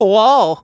08_luigi_whoa.aiff